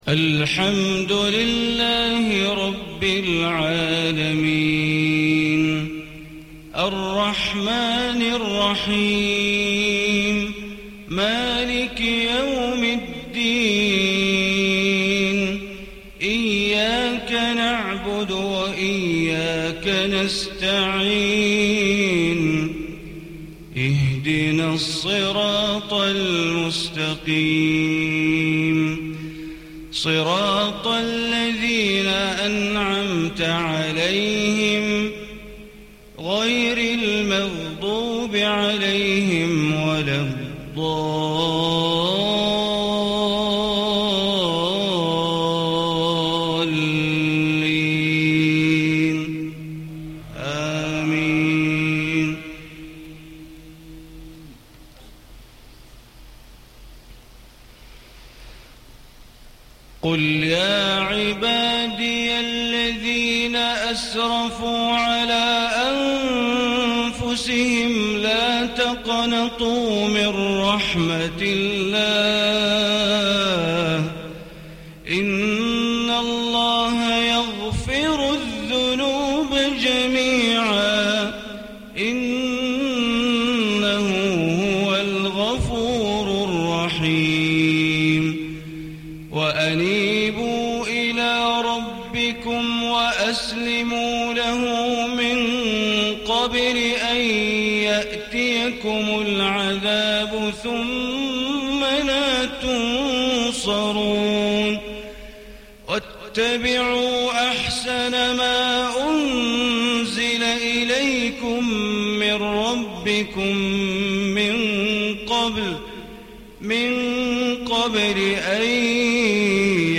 صلاة الفجر 1-1-1437هـ من سورة الزمر 53-70 > 1437 🕋 > الفروض - تلاوات الحرمين